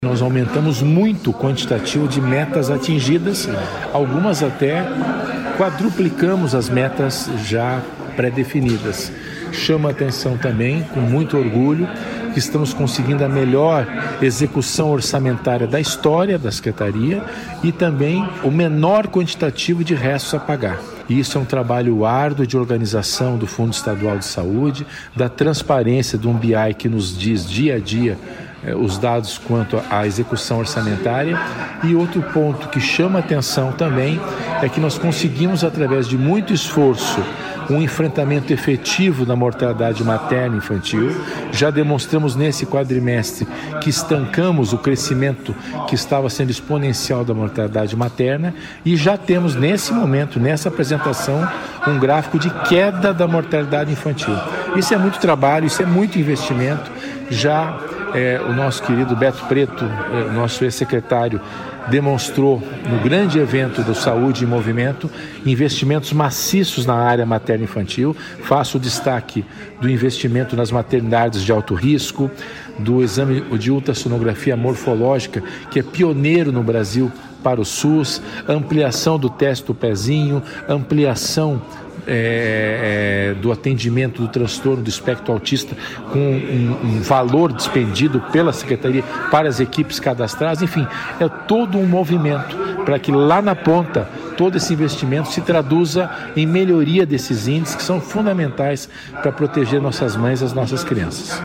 Sonora do secretário da Saúde, César Neves, sobre os resultados referentes ao terceiro quadrimestre de 2025